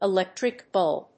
音節elèctric búlb